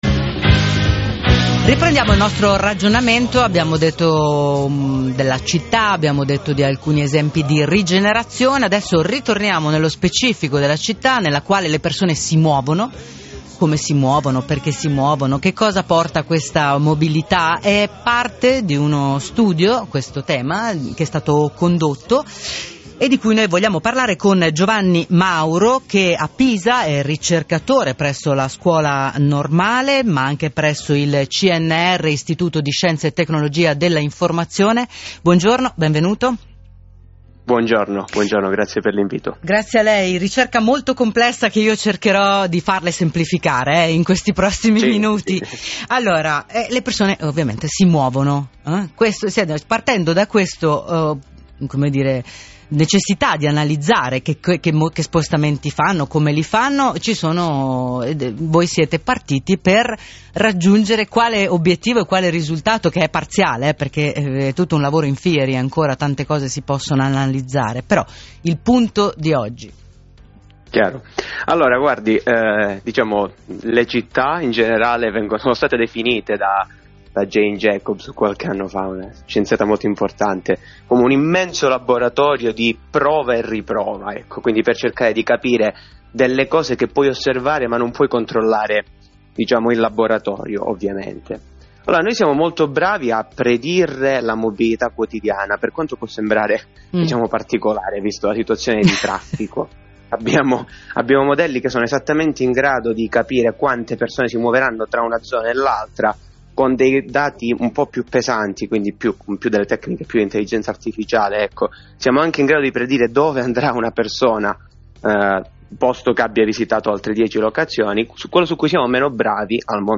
Radio 24 - Si può fare 09.05 del 15/12/2024 - Intervista